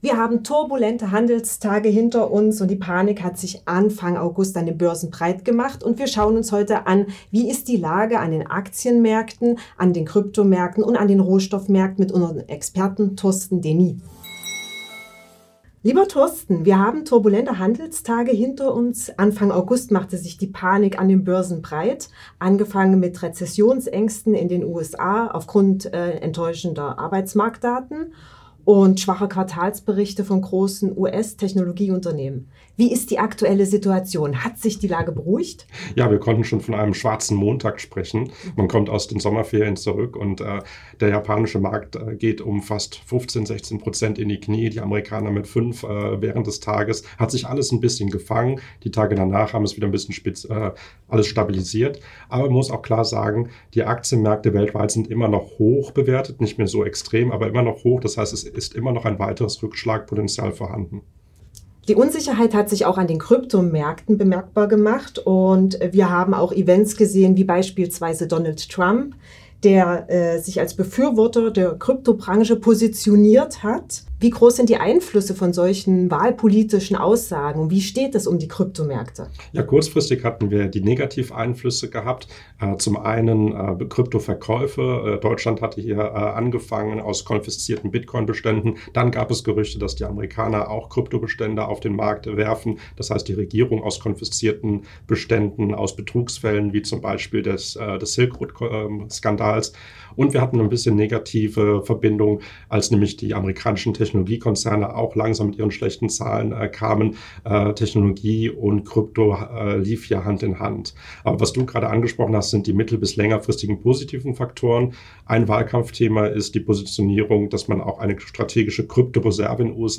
Einblicke im heutigen Experteninterview